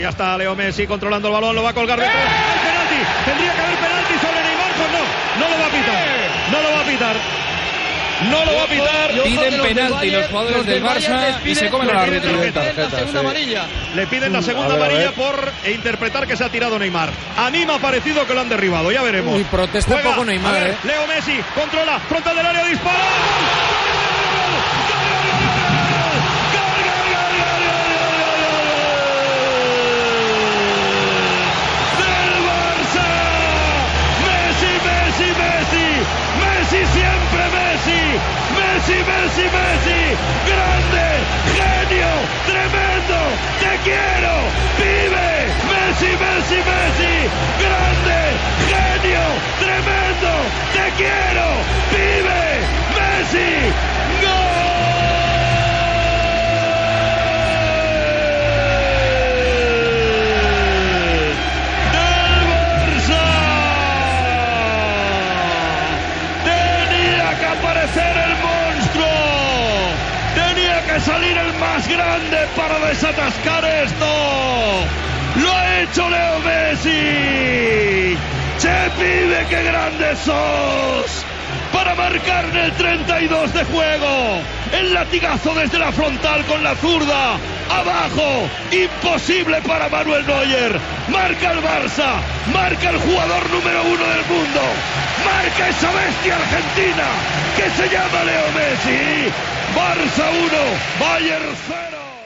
Transmissió del partit de la fase eliminatòria de la Copa d'Europa de futbol masculí entre el Futbol Club Barcelona i el Bayern München.
Minut 31 de la segona part. Narració del primer gol de Leo Messi.
Esportiu